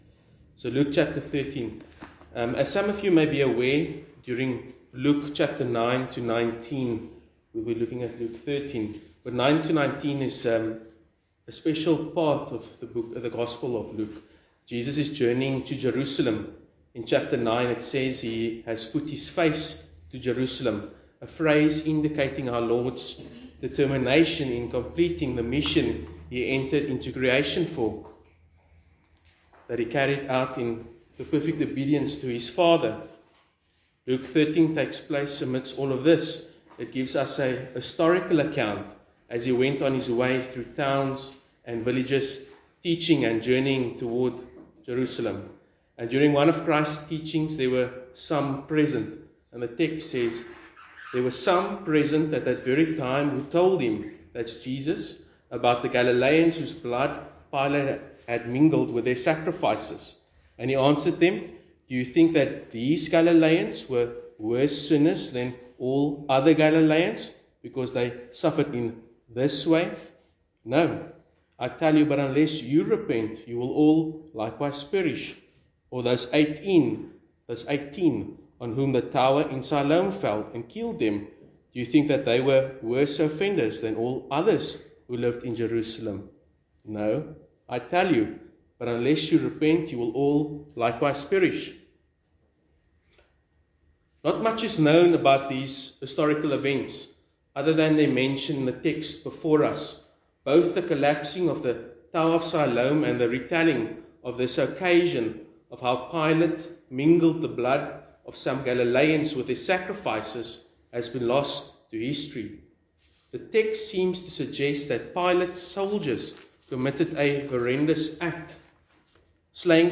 Good Friday 2023
Sermons under misc. are not part of a specific expositional or topical series.